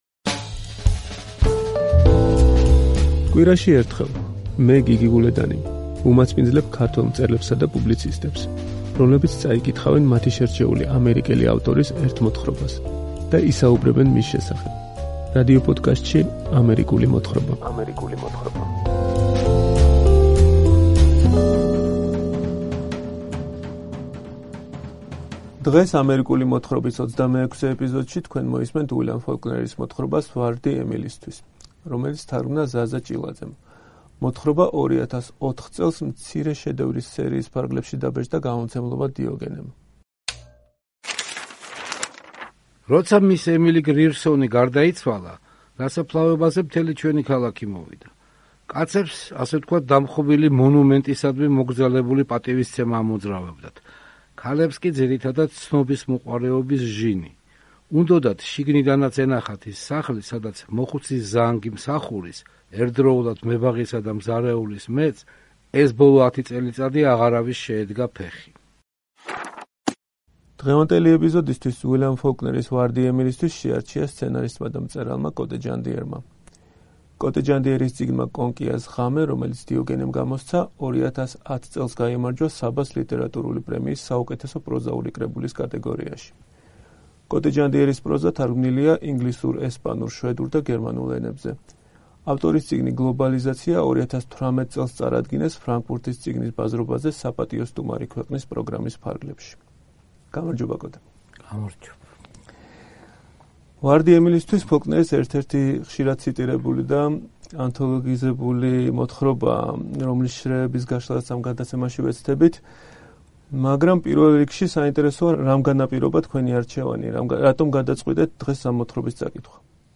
კითხულობს უილიამ ფოლკნერის მოთხრობას “ვარდი ემილისთვის”